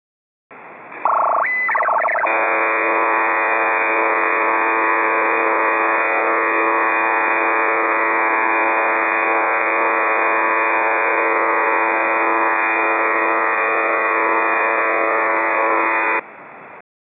сигнал на 7640